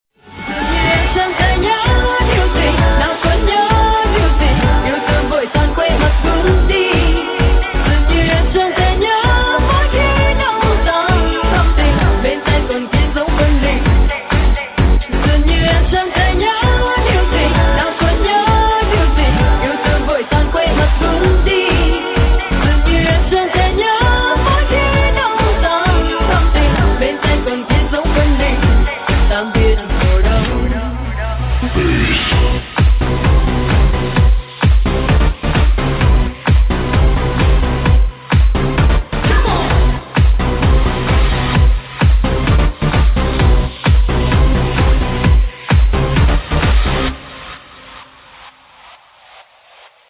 Nhạc chuông miễn phí